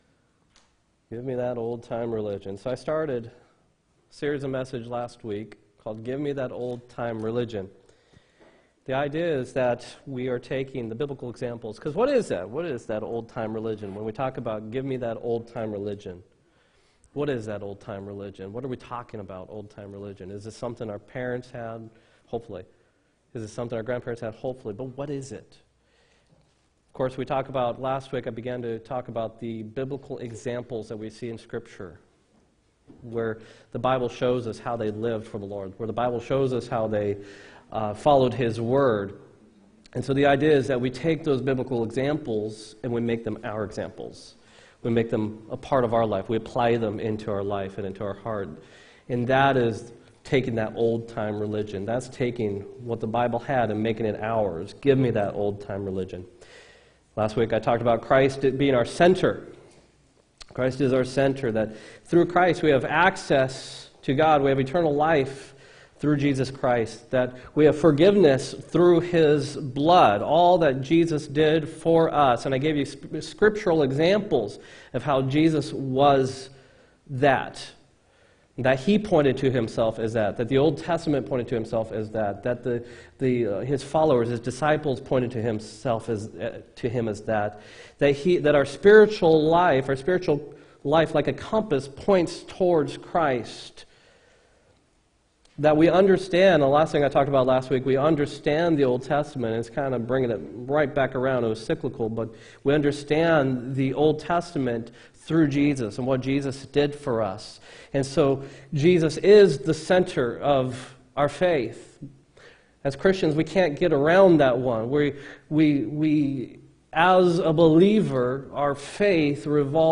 12-8-18 sermon
12-8-18-sermon.m4a